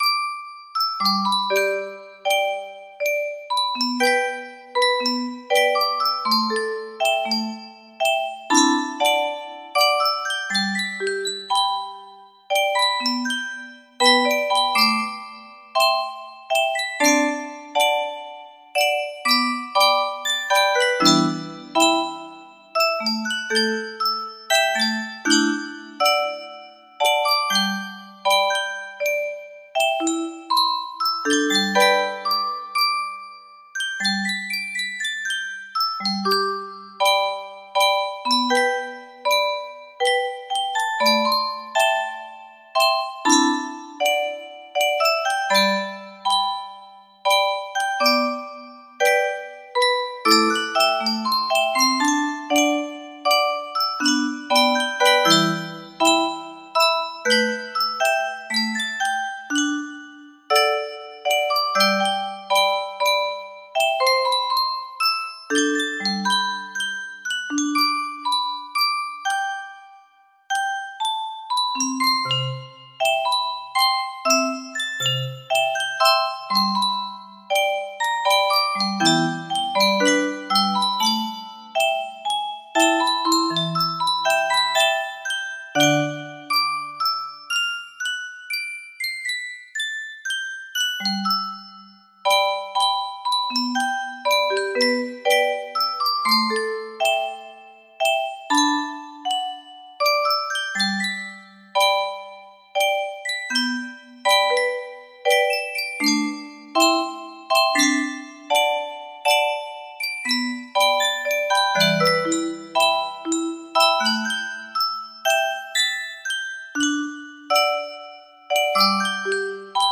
music box melody
Full range 60
Koto
Traditional Japanese stringed musical instrument